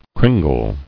[crin·gle]